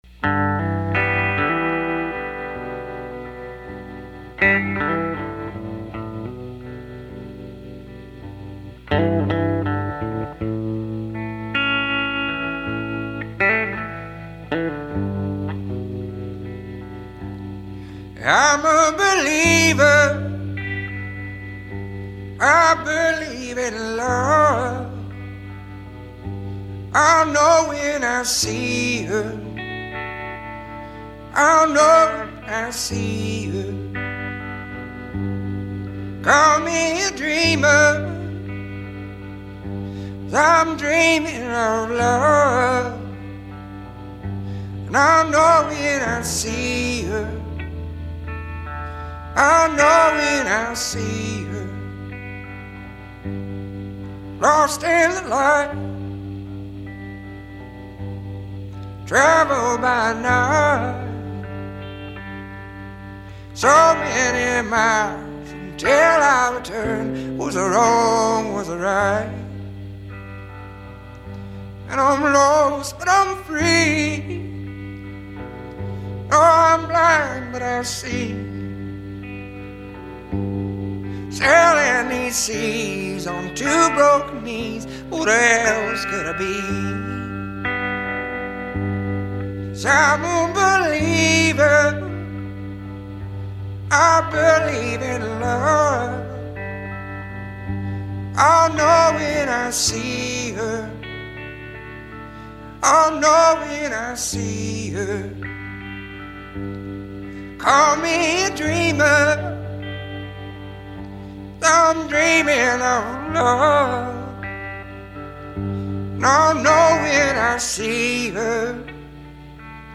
soul music